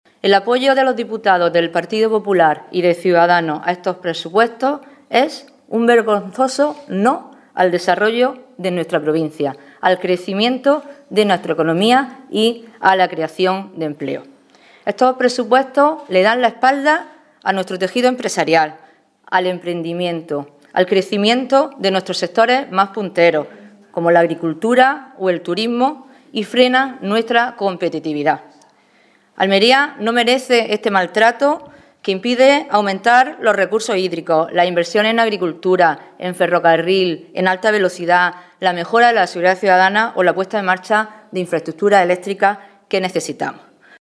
Declaraciones que ha ofrecido la diputada nacional del PSOE de Almería, Sonia Ferrer Tesoro, sobre la aprobación de los Presupuestos Generales del Estado